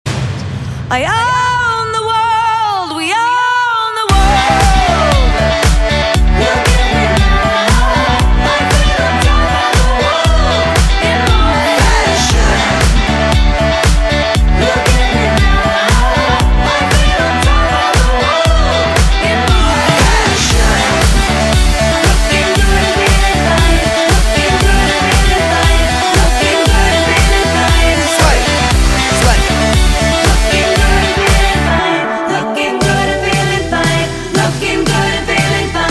M4R铃声, MP3铃声, 欧美歌曲 124 首发日期：2018-05-15 22:19 星期二